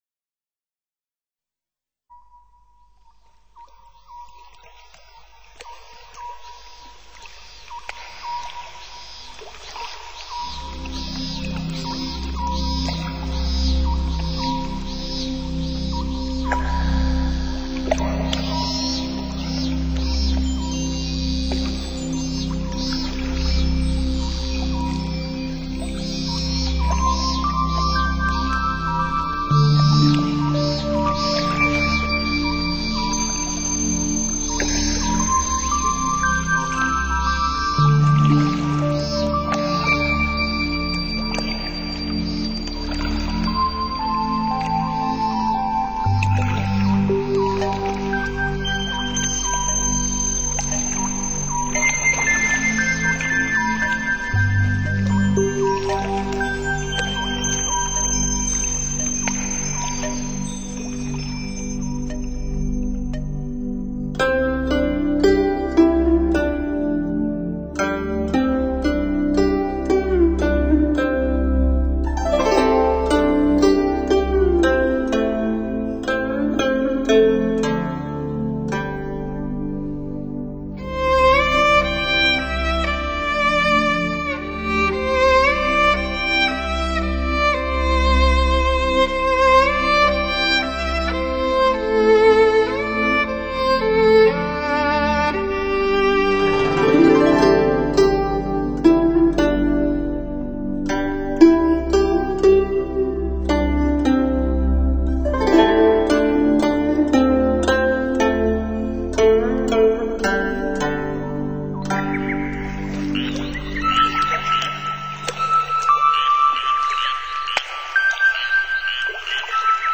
“自然声响”运用得宜，不落俗套。